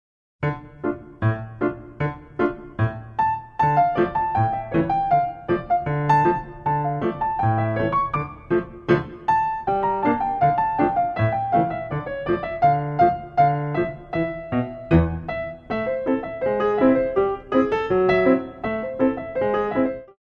Glissade Assemblé